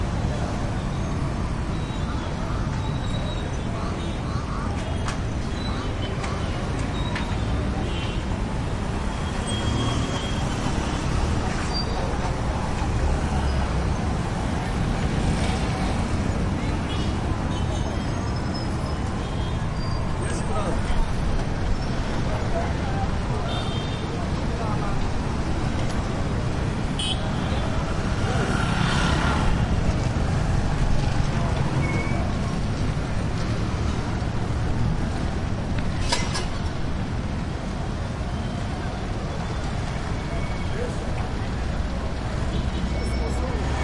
乌干达 " 交通 中等的上下坡街道 额外的转速或滑行袅袅的汽车 货车 摩托车 坎帕拉，乌干达，非洲 20
描述：交通媒体上下山街道额外转速或沿着蜿蜒的汽车面包车摩托车坎帕拉，乌干达，非洲2016.wav
标签： 乌干达 交通 街道 非洲 汽车 摩托车 沙哑 货车 城市
声道立体声